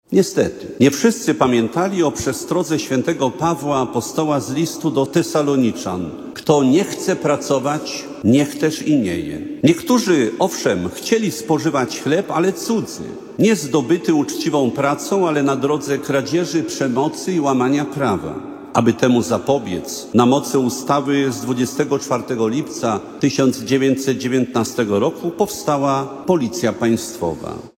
O godzinie 9:00 w warszawskiej bazylice Świętego Krzyża na Krakowskim Przedmieściu odbyła się Msza święta kończąca centralne obchody setnej rocznicy powołania Policji Państwowej.
Mszy świętej przewodniczył i kazanie wygłosił bp Józef Guzdek, biskup polowy Wojska Polskiego.